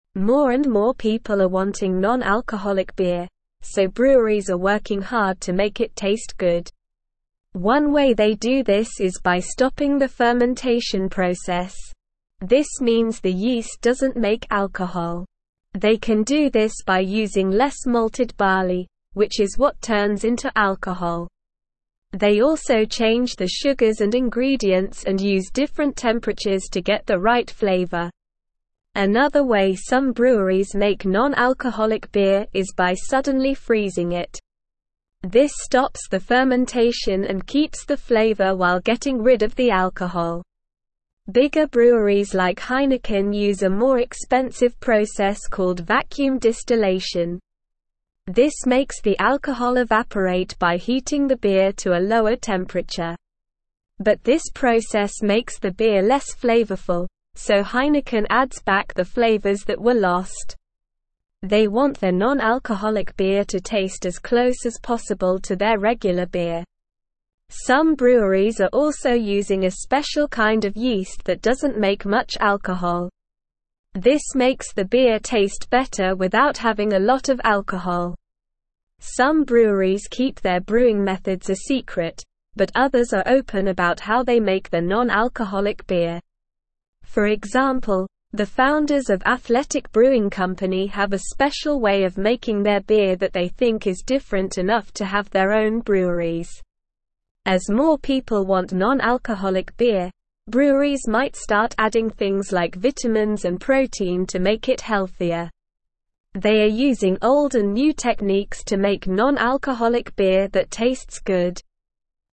Slow